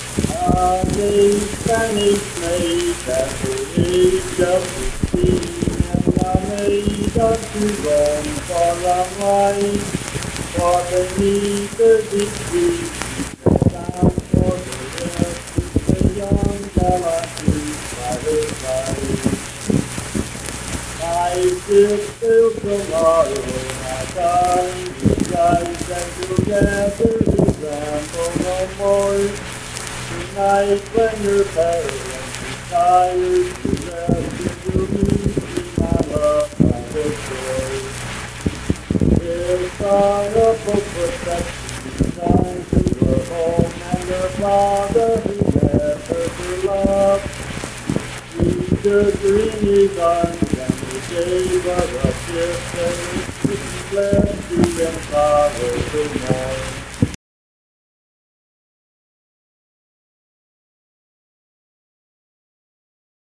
Unaccompanied vocal music performance
Verse-refrain 3(4).
Voice (sung)